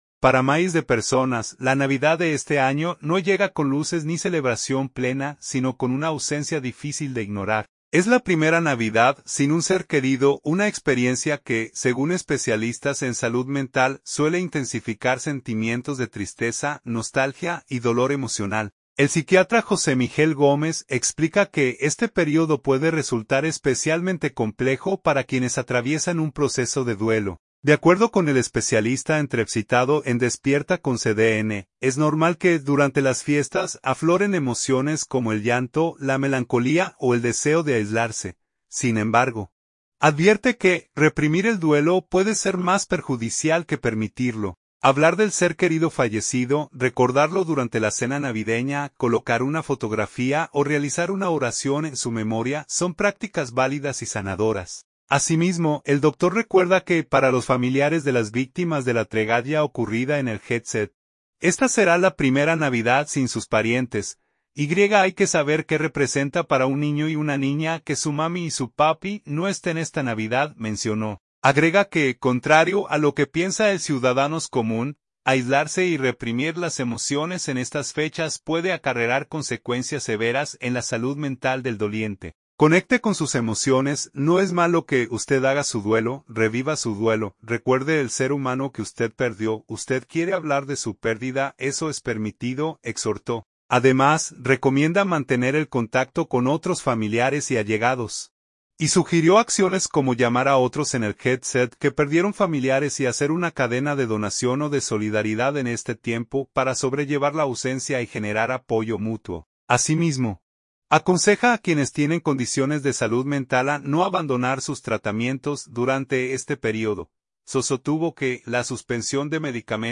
De acuerdo con el especialista entrevsitado en Despierta con CDN, es normal que durante las fiestas afloren emociones como el llanto, la melancolía o el deseo de aislarse.